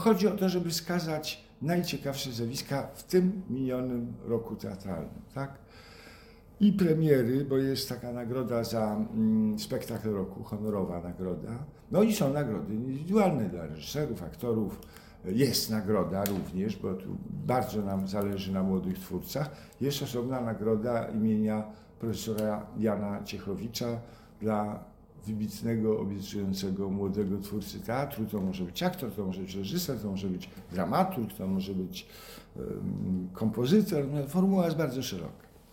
W załączniku znajduje się wypowiedź Władysława Zawistowskiego dyrektora Departamentu Kultury UMWP.